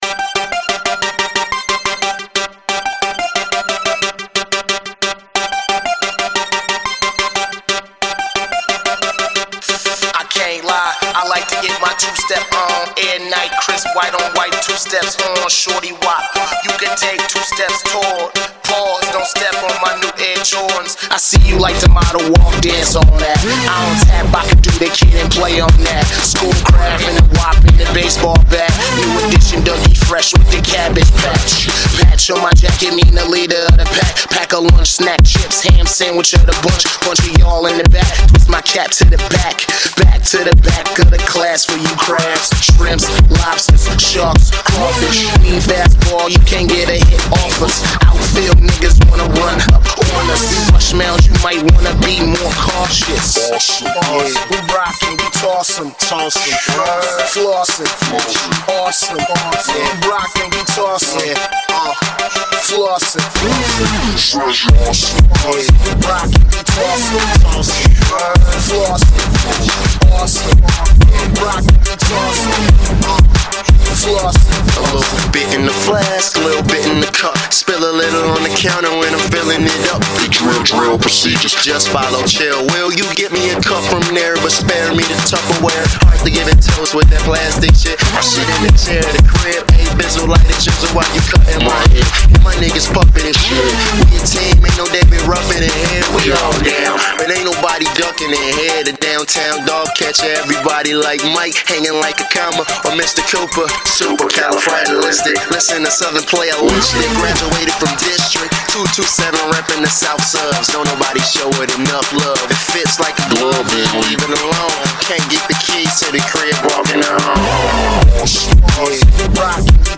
an Italian producer from the hamlet of Bassano del Grappa
his electronic alter ego